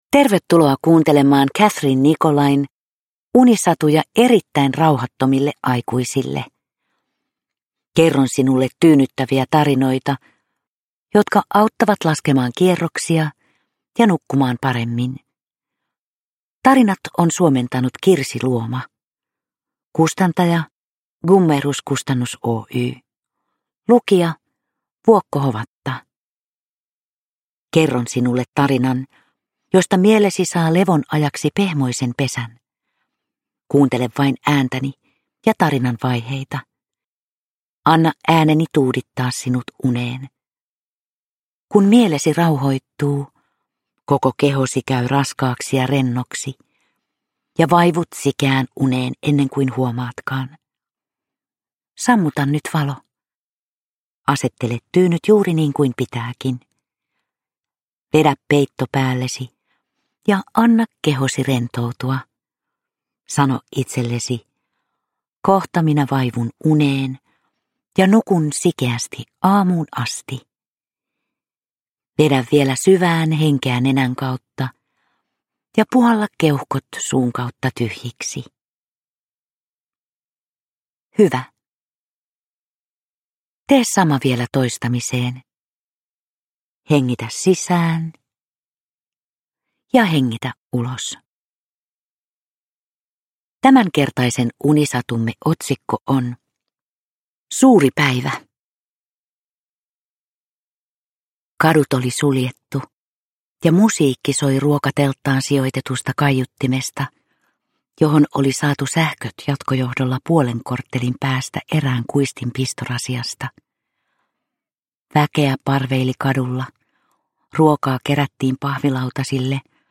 Unisatuja erittäin rauhattomille aikuisille 10 - Suuri päivä – Ljudbok
Vuokko Hovatan tyyni ääni saattelee kuulijan lempeästi unten maille.
Uppläsare: Vuokko Hovatta